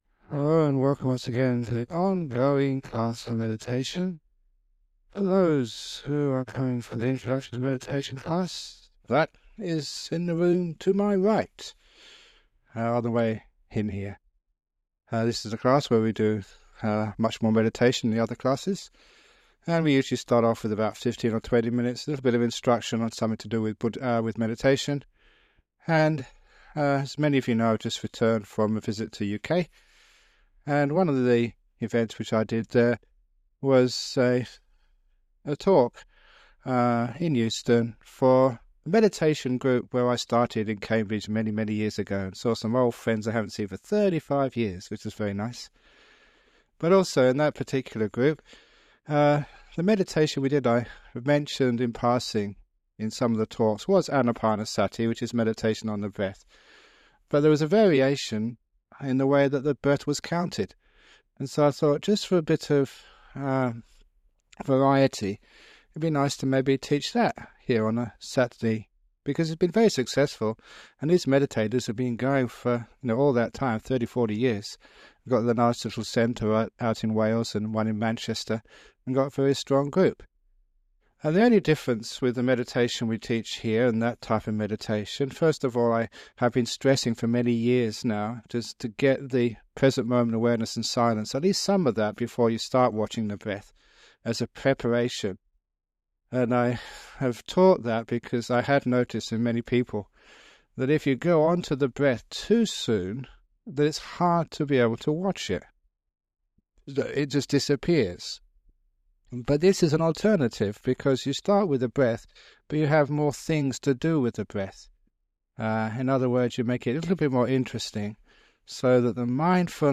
It includes a talk about some aspect of meditation followed by a 45 minute guided meditation.